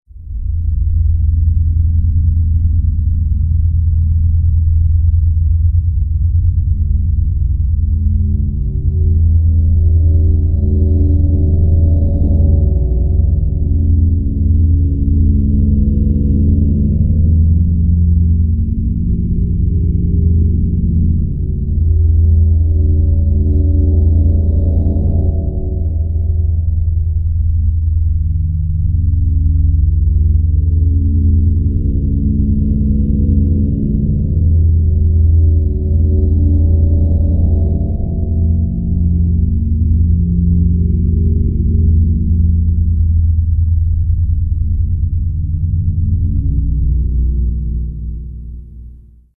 AMBIENTE MISTERIOSO
Tonos EFECTO DE SONIDO DE AMBIENTE de AMBIENTE MISTERIOSO
Ambiente_misterioso.mp3